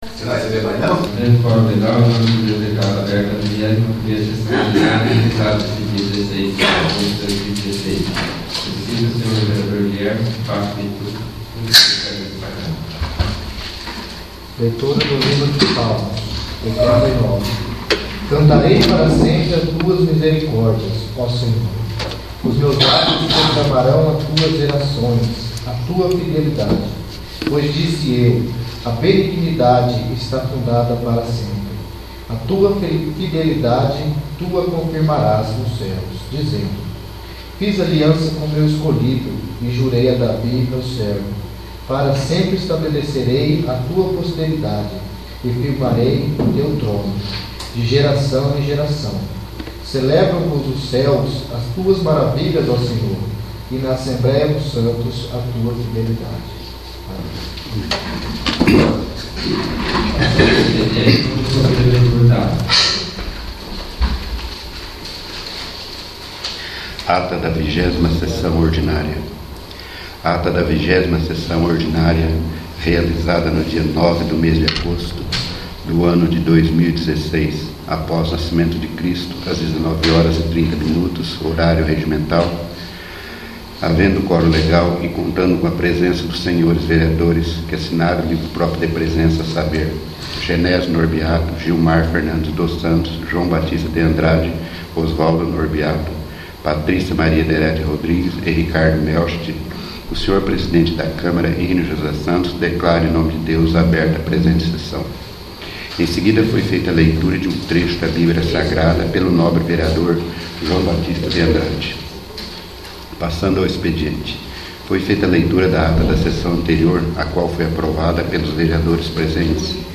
21º. Sessão Ordinária